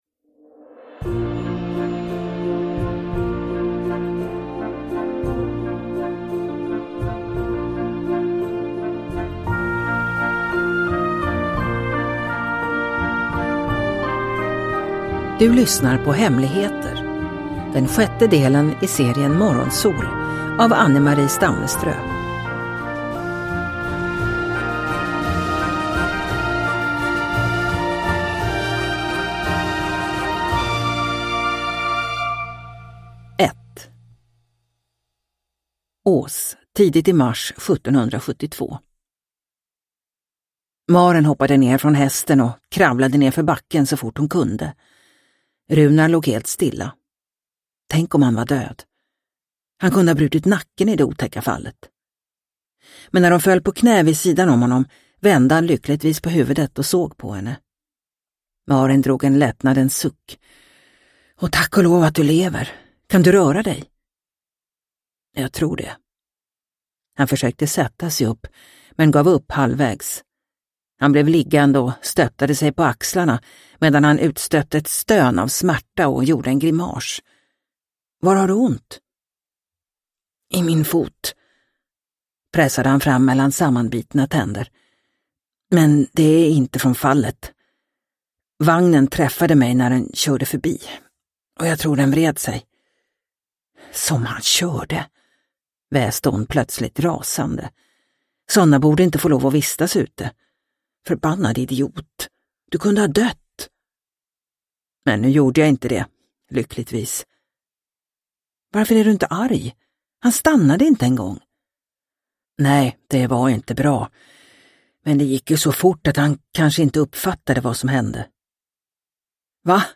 Hemligheter – Ljudbok – Laddas ner